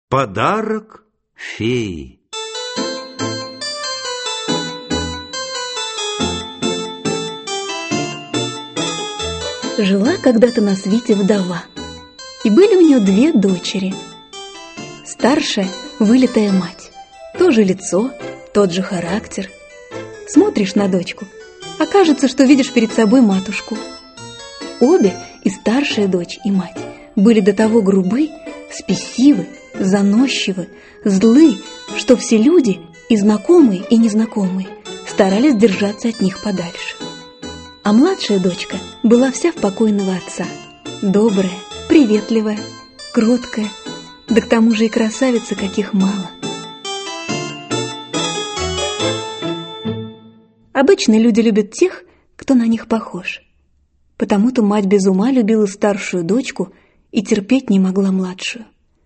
Аудиокнига Волшебные сказки Шарля Перро | Библиотека аудиокниг